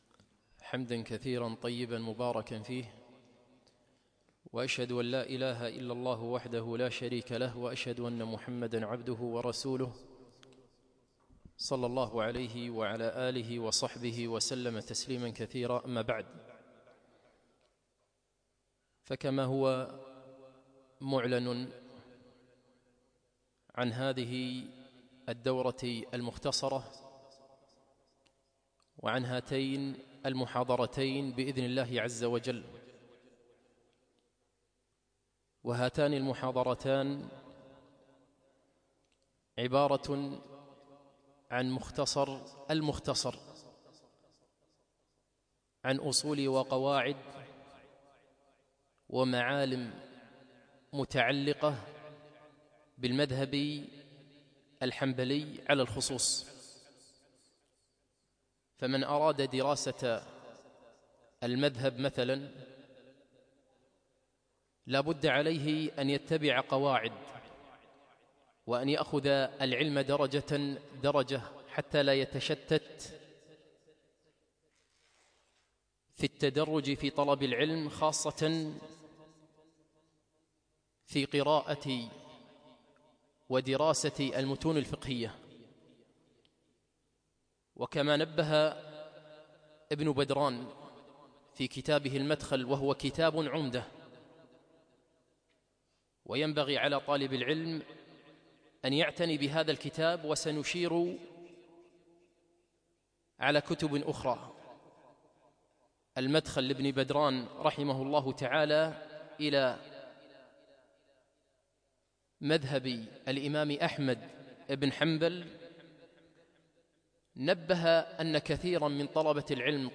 يوم الأحد 8 جمادى أول 1438 الموافق 5 2 2017 في مسجد العلاء بن عقبة الفردوس